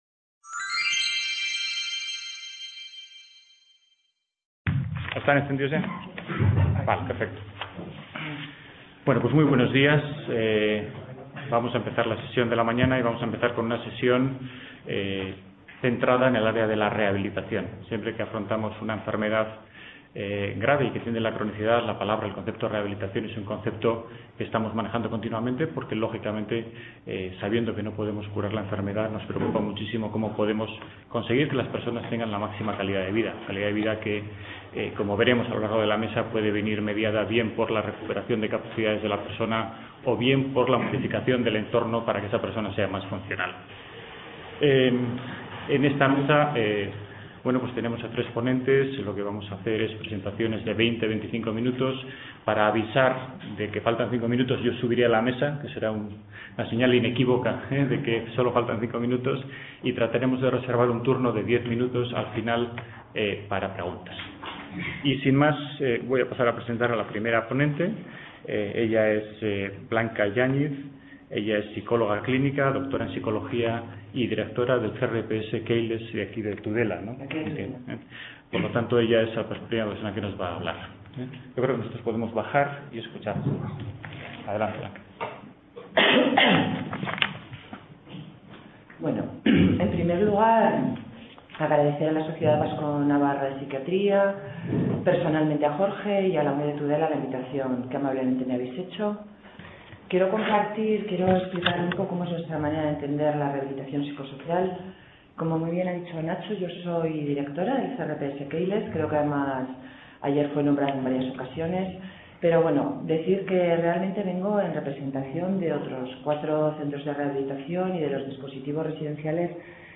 Enfermedad Mental: Integración Familiar y Laboral - XV Reunión Anual de la Sociedad Vasco Navarra de Psiquiatría